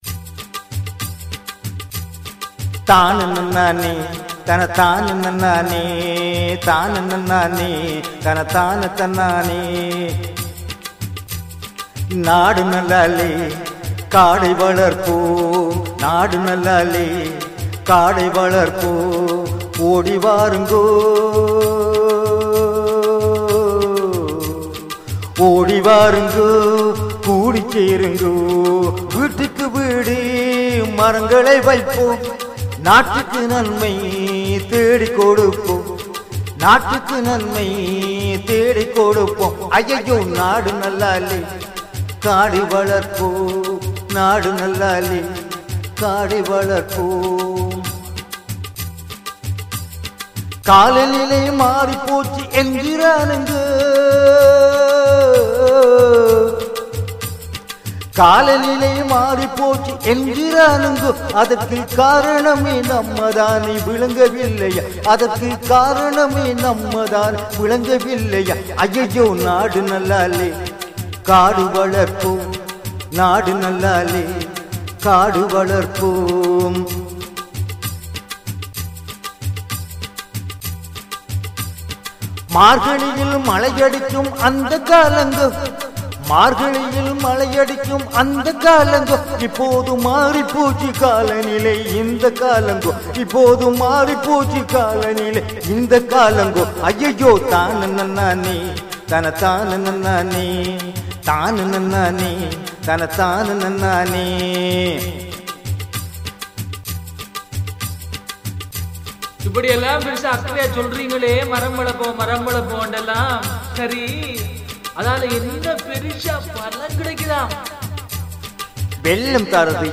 எனது ஒரு பாடலை தனது குரலில் அழகாக தந்துள்ளார்.